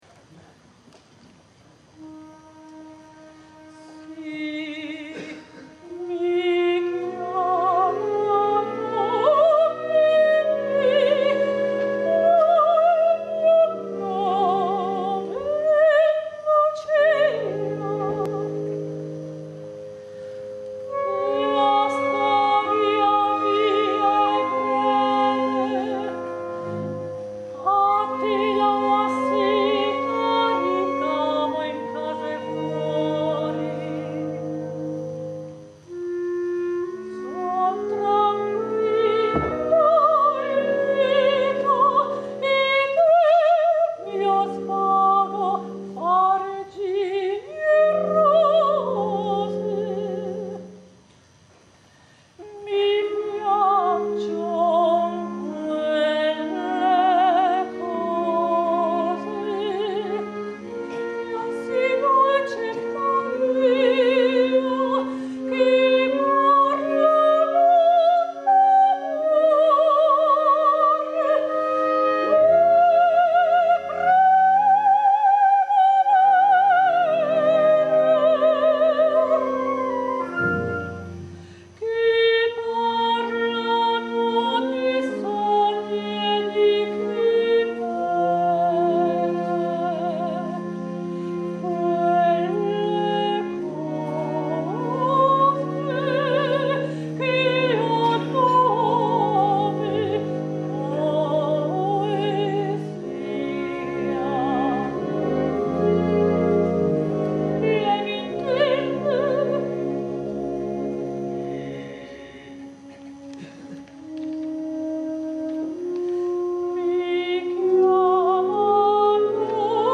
soprano solista
registrato dal vivo: Teatro Vittoria